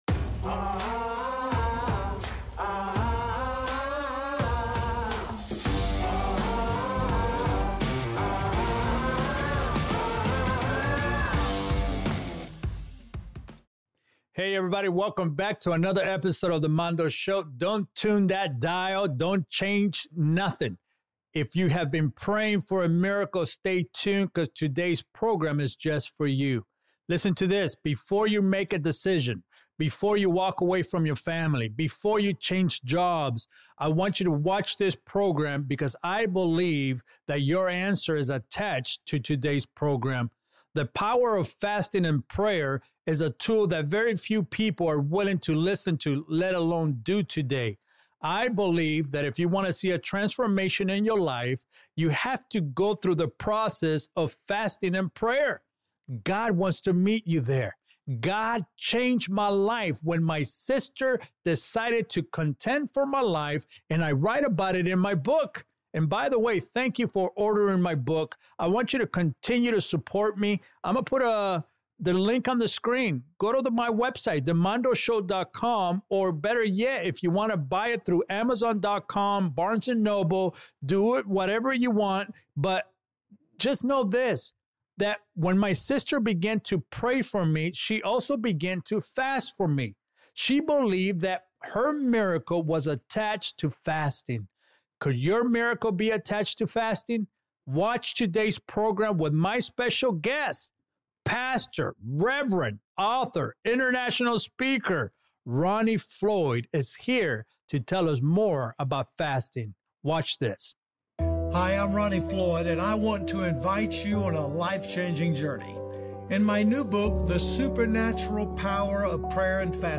Learn how these spiritual disciplines can unlock the miraculous, transform lives, and bring answers to prayers you’ve been waiting for. Don’t miss this inspiring conversation about faith, leadership, and God’s supernatural power at work!